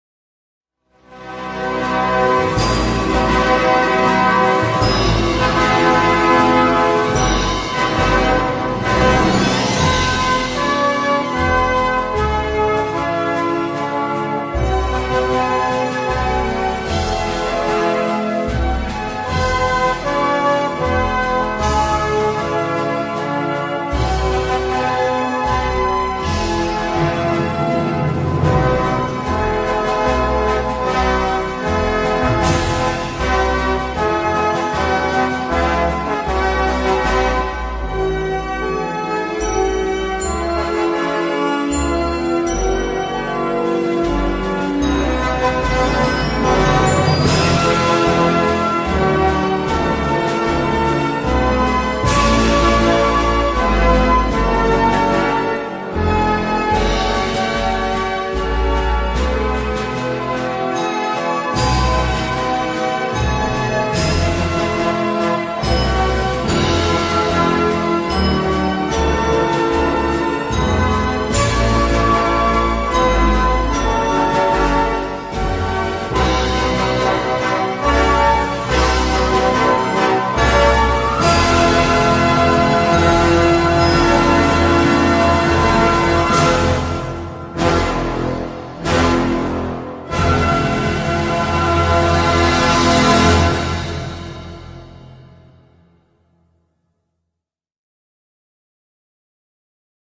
инструментальное исполнение